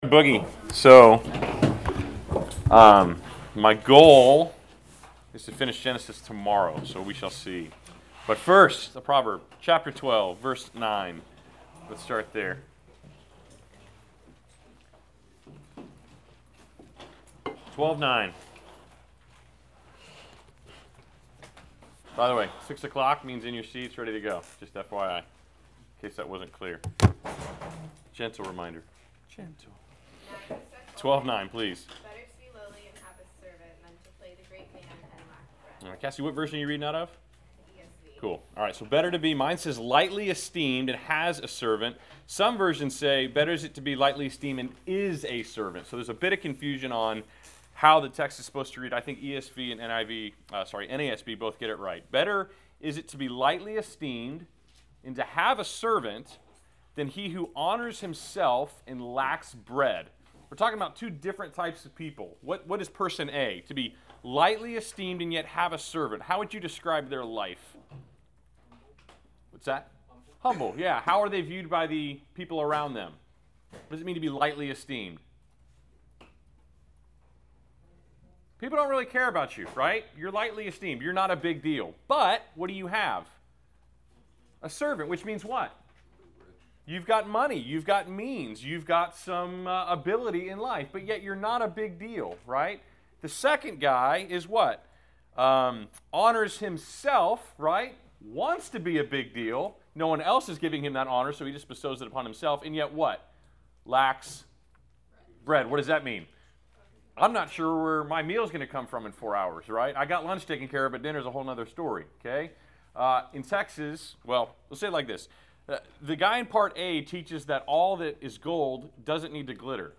Class Session Audio October 15